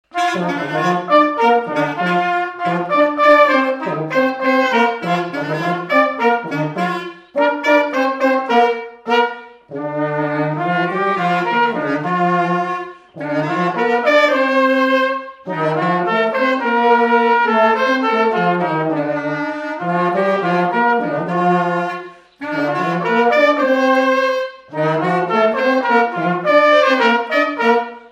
Mémoires et Patrimoines vivants - RaddO est une base de données d'archives iconographiques et sonores.
Résumé instrumental
danse : quadrille
Pièce musicale inédite